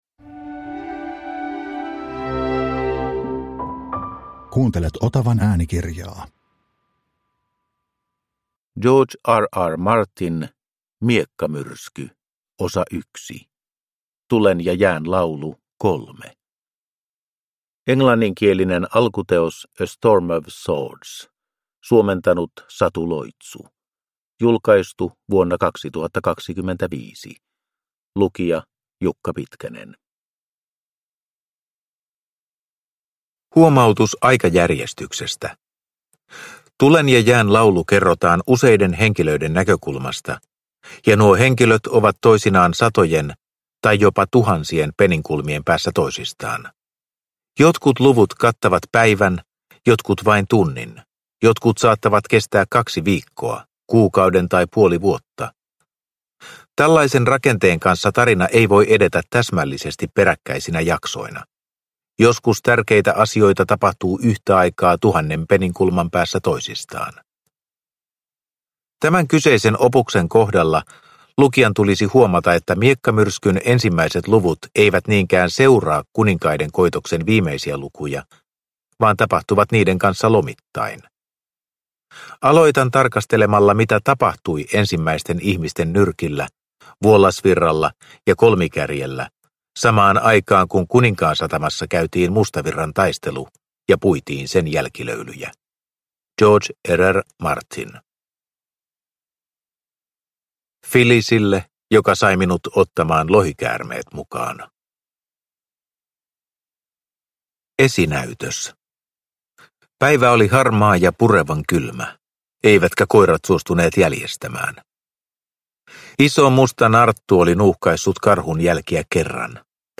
Miekkamyrsky 1 – Ljudbok
Äänikirjan ensimmäinen osa.